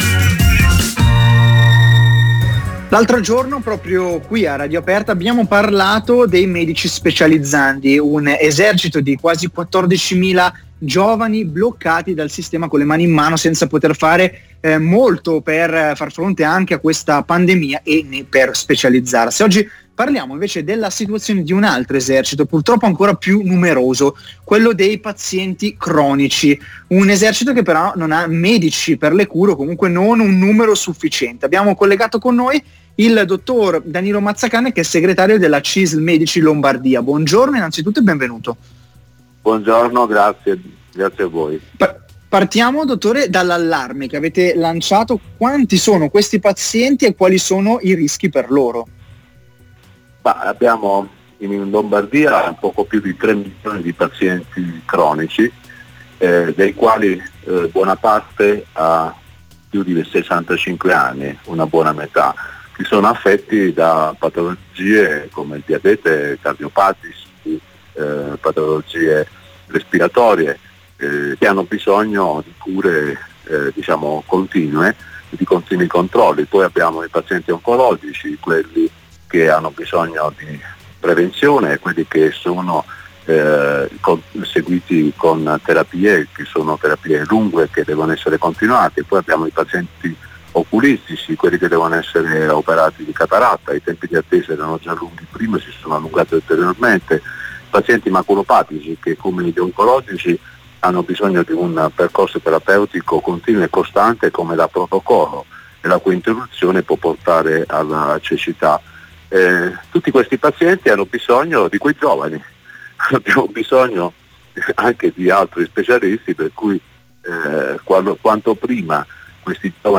Questa mattina se n’è parlato anche a Marconi Radio Aperta.